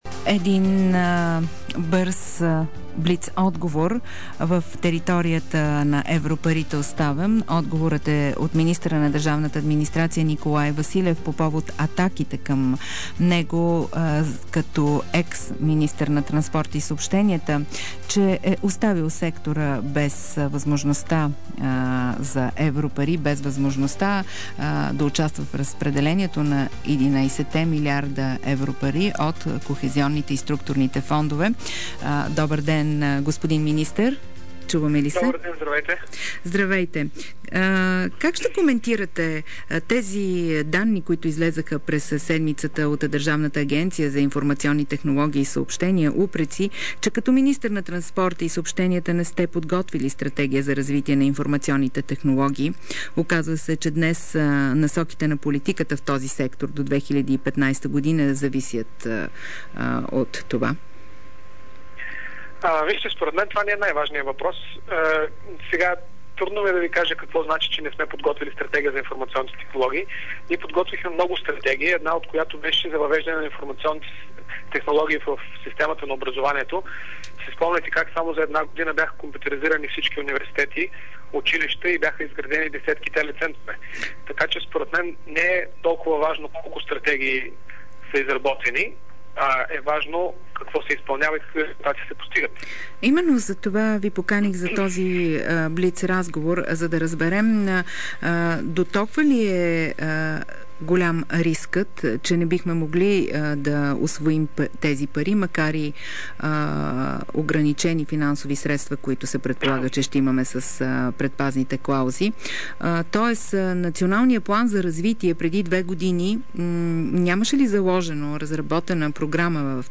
DarikNews audio: Николай Василев, министър на държавната администрация в интервю за предаването „Портфейл”.